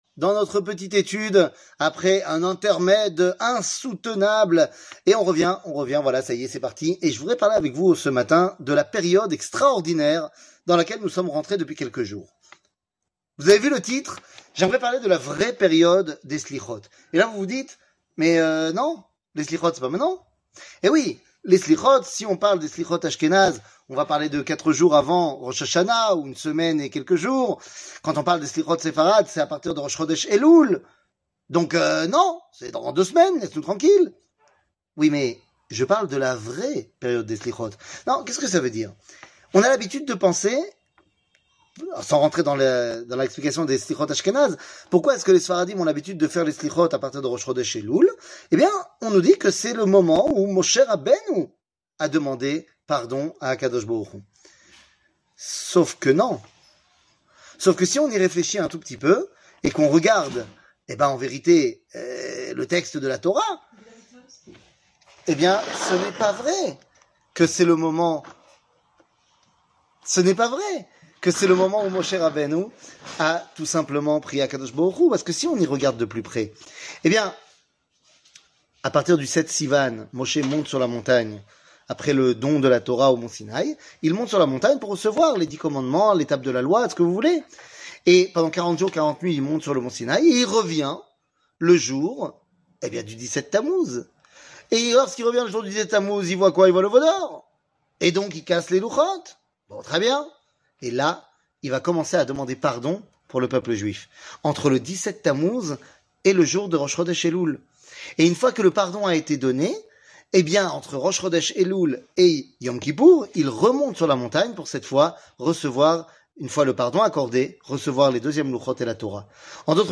שיעור מ 06 אוגוסט 2023
שיעורים קצרים